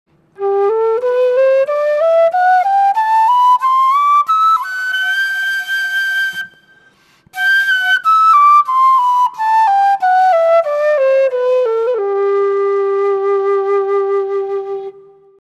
Кена (Quena, Amaru, G) Перу
Кена (Quena, Amaru, G) Перу Тональность: G
Кена - продольная флейта открытого типа, распространённая в южноамериканских Андах.